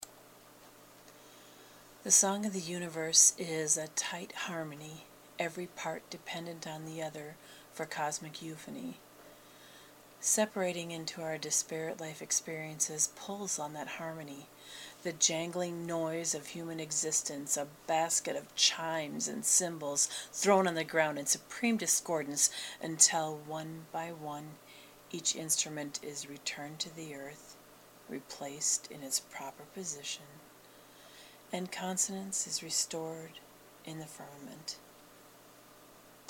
Putting words here in case you have issues understanding my Minnesotan accent.